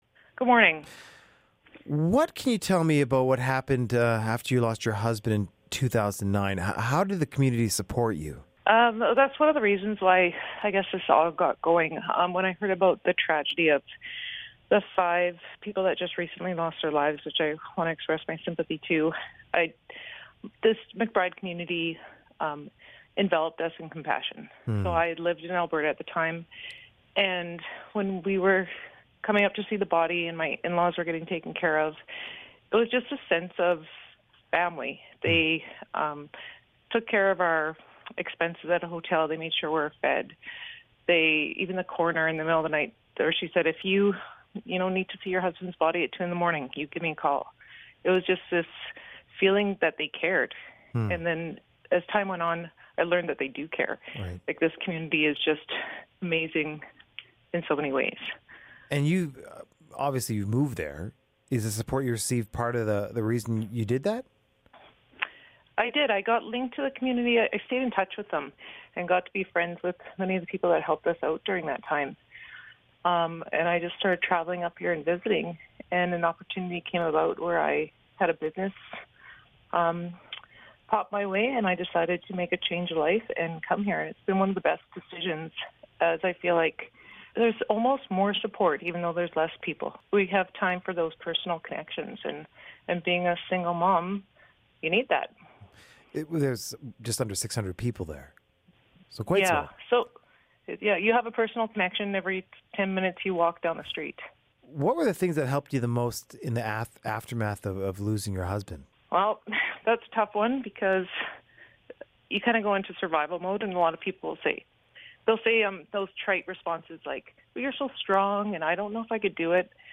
Daybreak North from CBC Radio